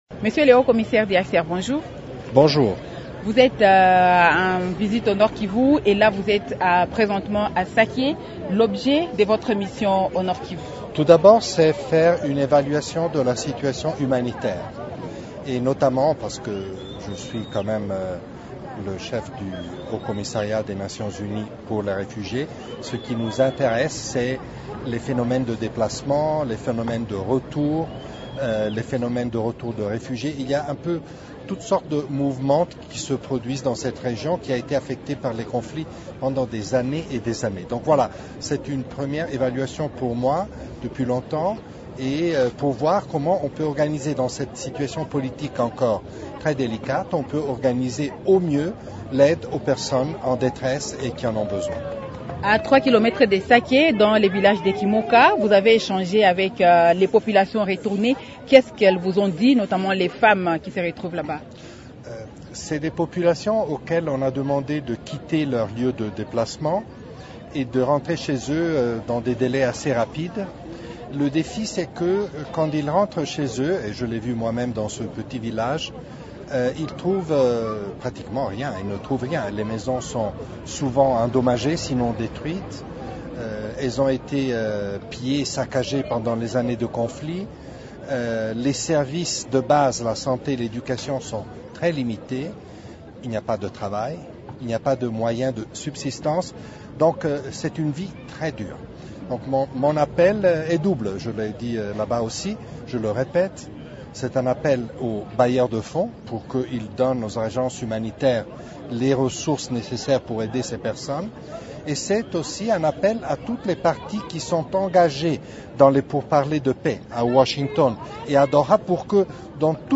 A l'issue de sa visite, il a eu une interview avec Radio Okapi.